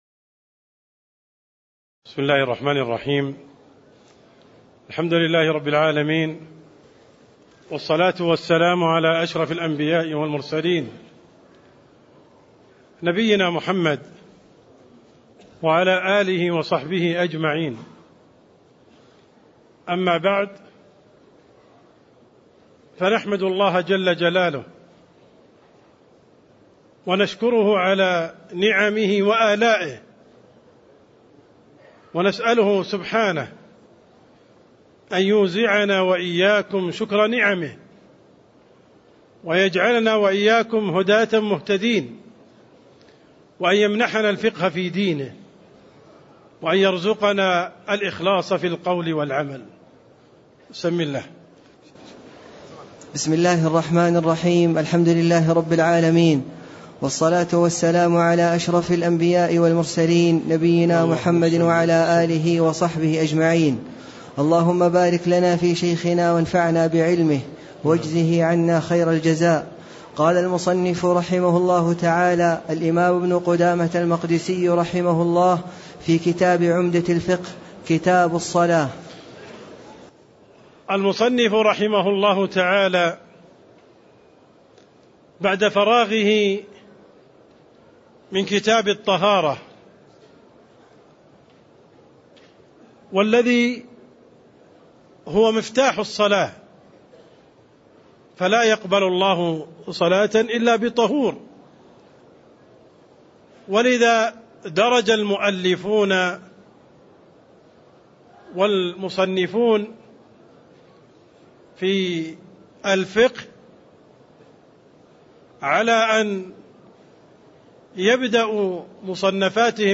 تاريخ النشر ٧ ذو القعدة ١٤٣٥ هـ المكان: المسجد النبوي الشيخ: عبدالرحمن السند عبدالرحمن السند مقدمة كتاب الصلاة (02)